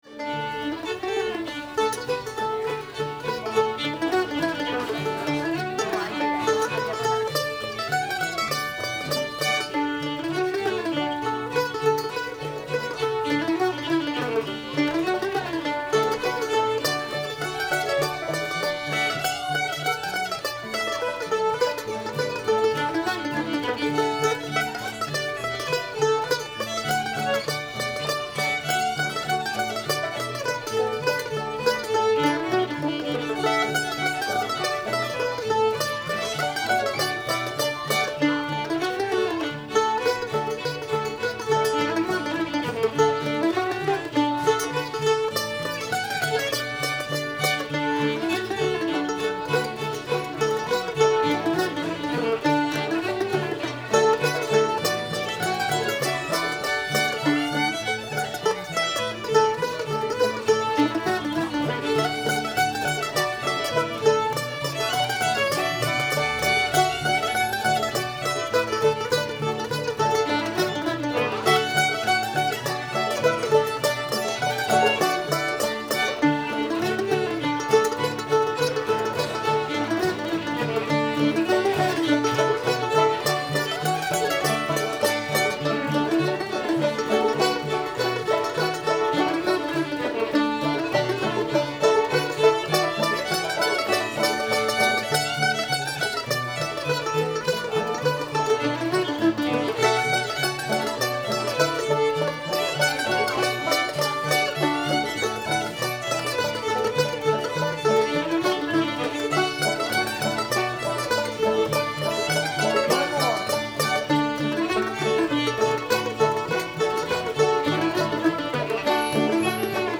sweets o'weaver [D]